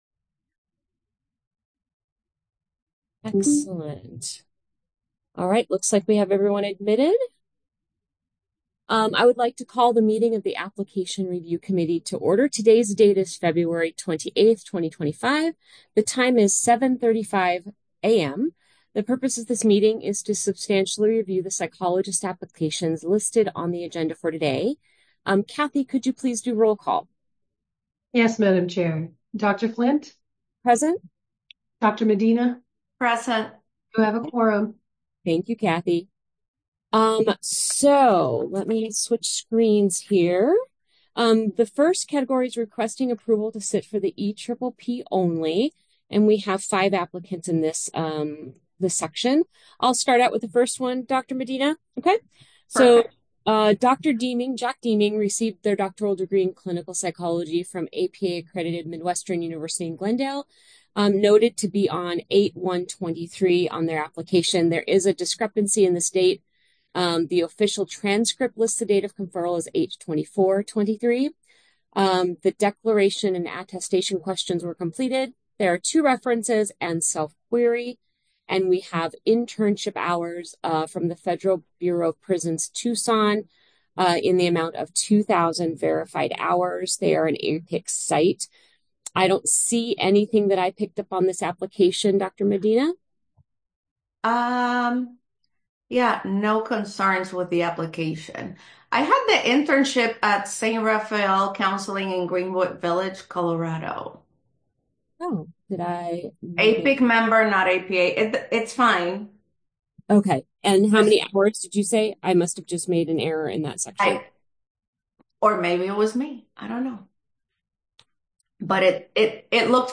The meeting is being held virtually via Zoom.